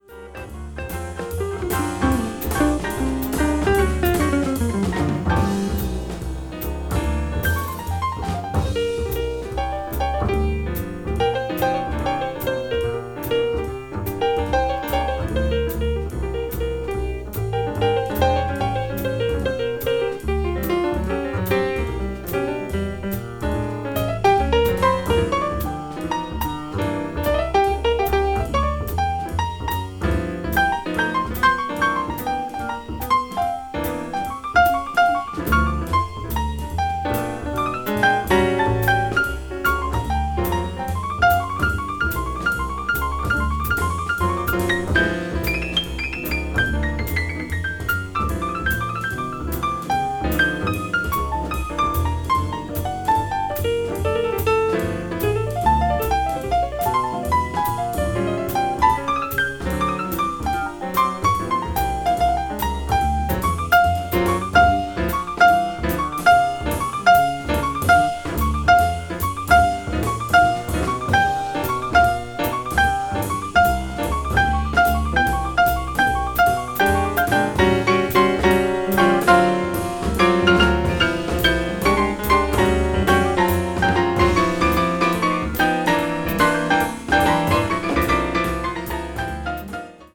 piano
bass
drums
which sways at a medium tempo
blues jazz   hard bop   modern jazz